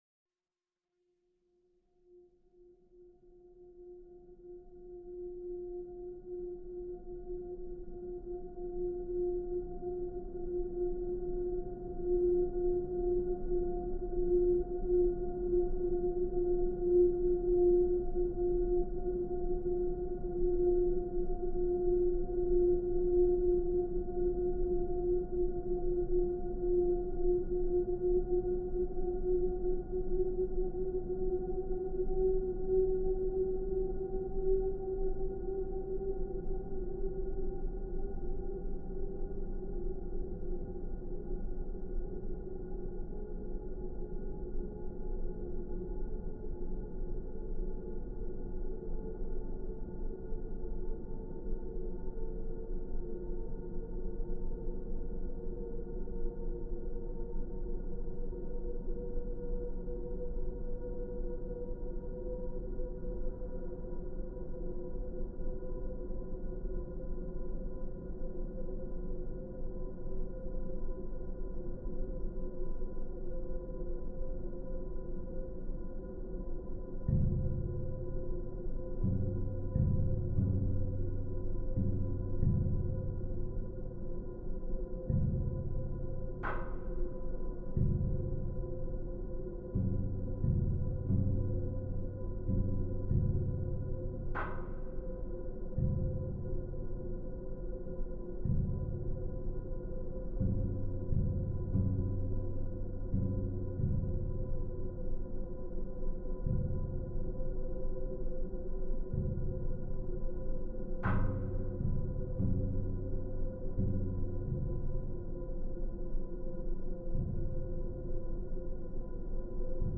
Film music: